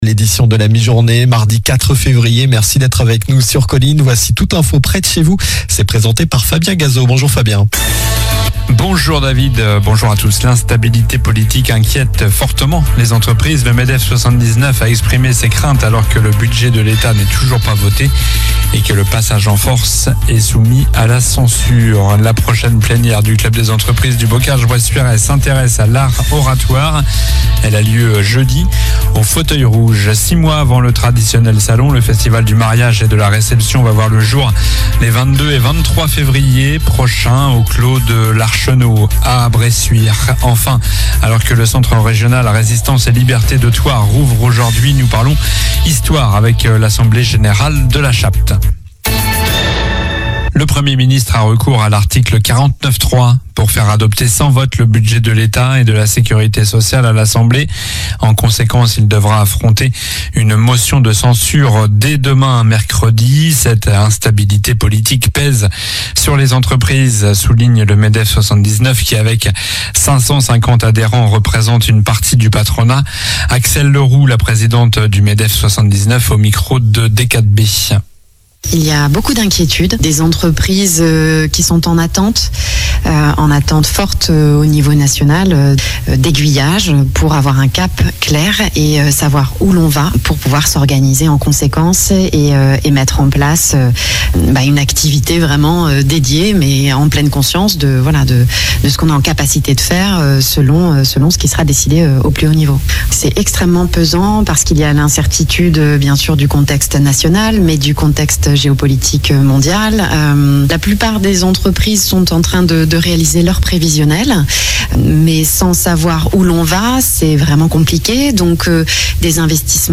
Journal du mardi 04 février (midi)